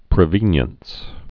(prĭ-vēnyəns)